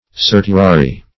Certiorari \Cer`ti*o*ra"ri\, n. [So named from the emphatic word